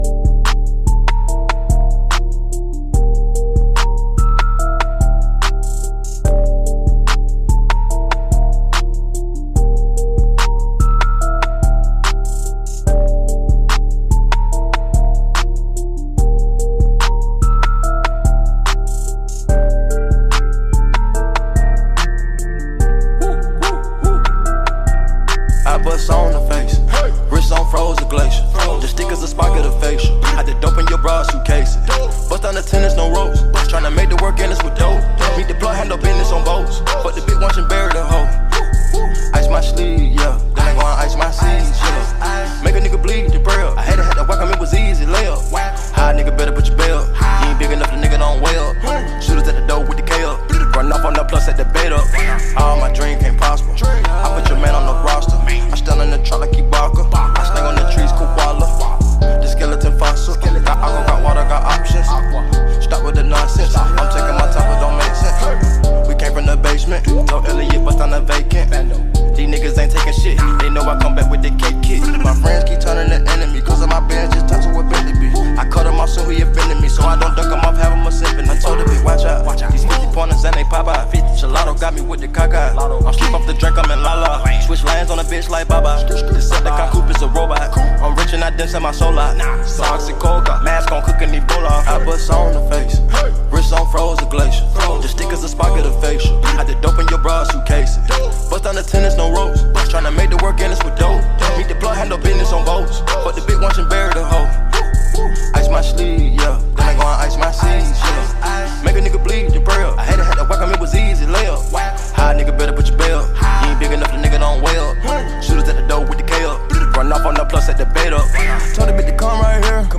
American rapper
hip hop